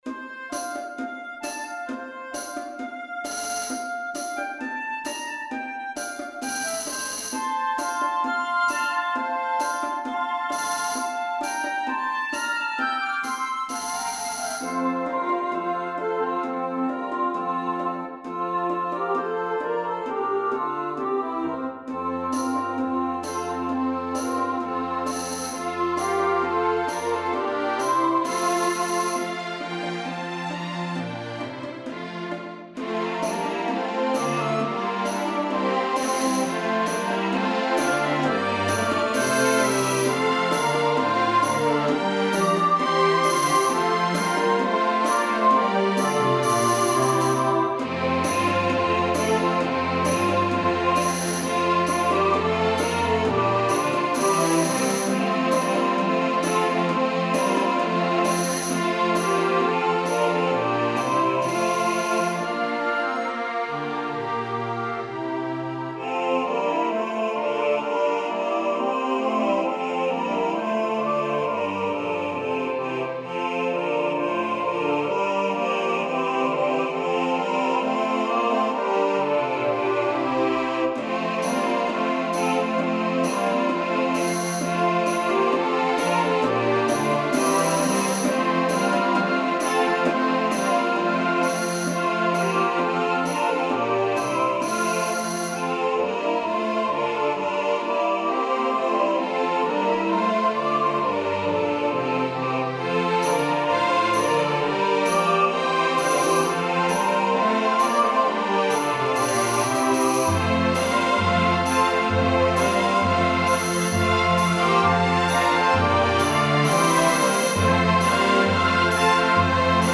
for SATB Choir & Small Orchestra
Synthesized MP3 File:
Composer: Trad. French Carol